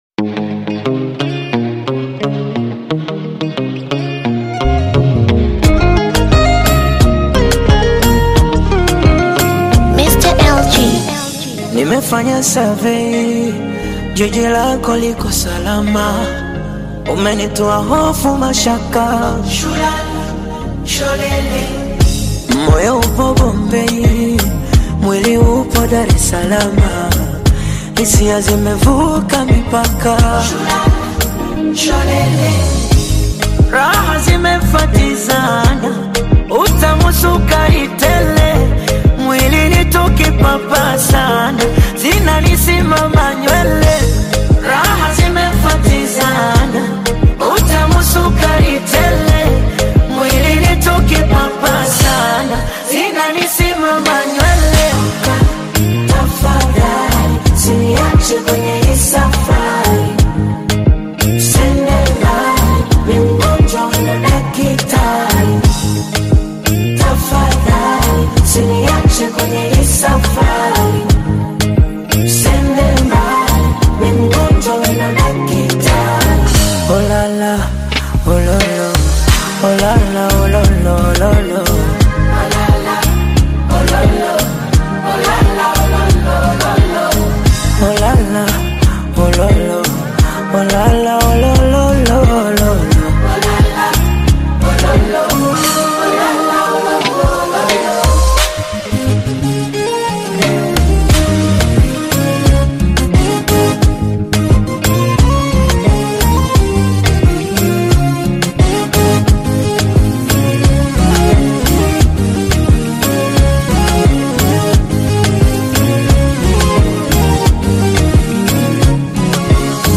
Tanzanian Bongo Flava
Bongo Flava You may also like